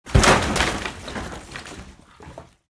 Звуки стены
Звук рушащейся стены